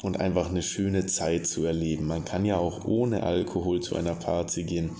Nexdata/German_Conversational_Speech_Data_by_Mobile_Phone at main